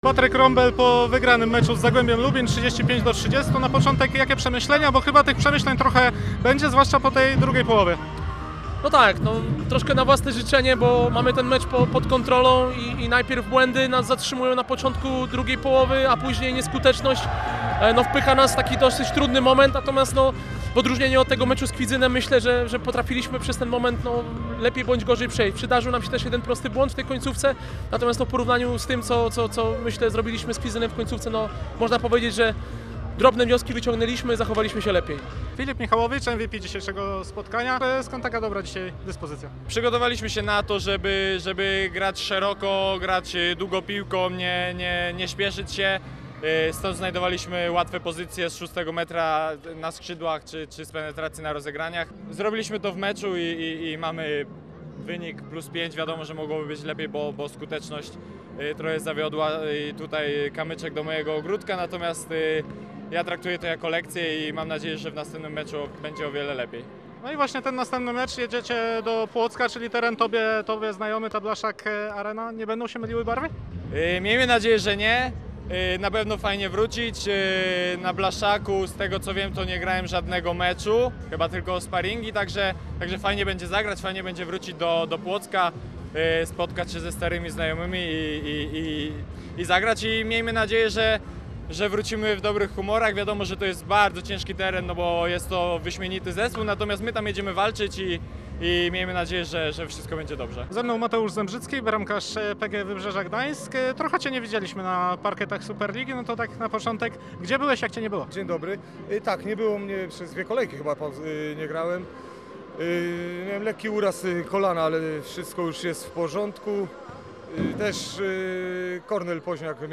Zachęcamy do wysłuchania pomeczowej rozmowy z najważniejszymi postaciami tego spotkania: